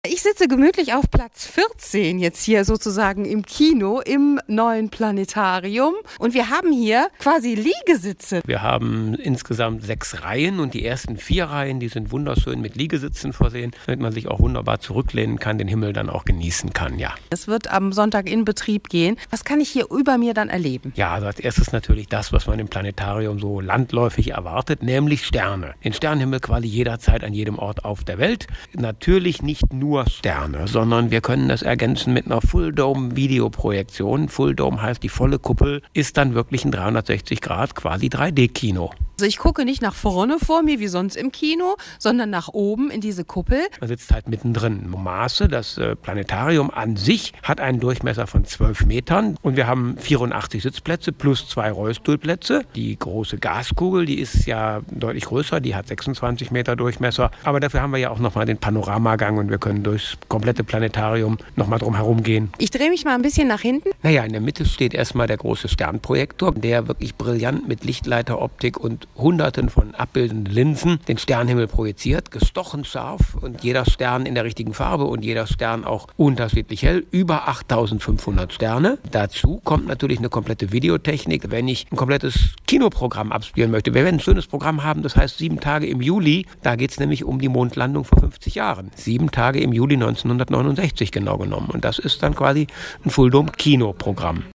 Wir haben mal Probe gesessen im Planetarium